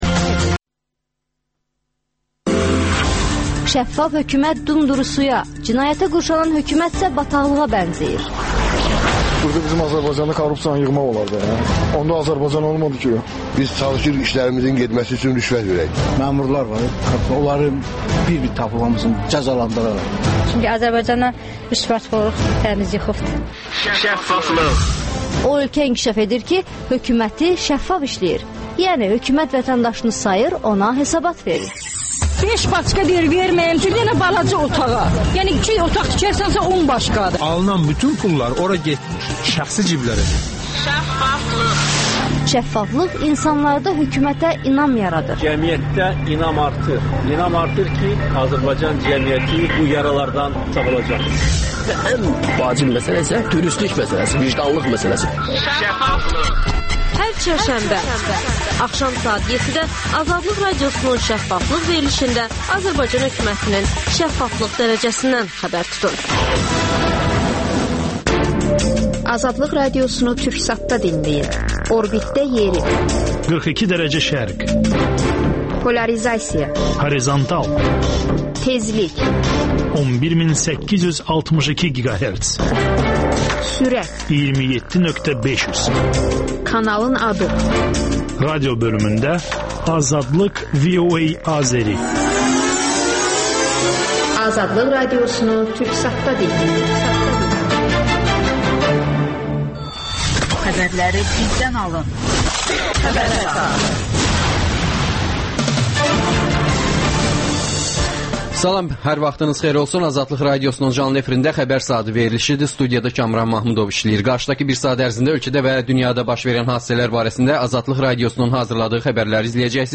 AzadlıqRadiosunun müxbirləri ölkə və dünyadakı bu və başqa olaylardan canlı efirdə söz açırlar.